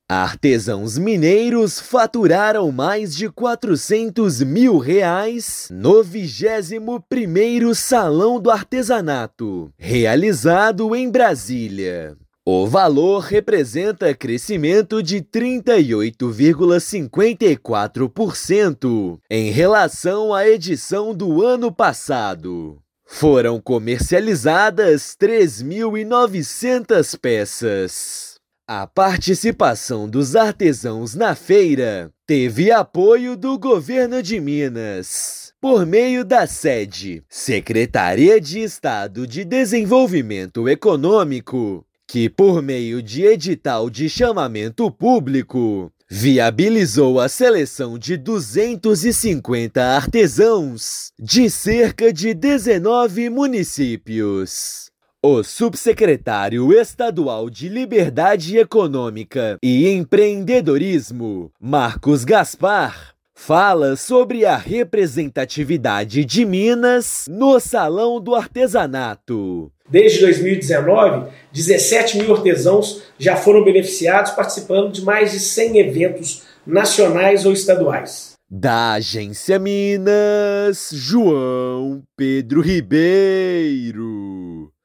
[RÁDIO] Artesãos mineiros faturam mais de R$ 406 mil durante o 21º Salão do Artesanato de Brasília
Além de Belo Horizonte, artesãos de outros 18 municípios tiveram participação gratuita em estande no evento. Ouça matéria de rádio.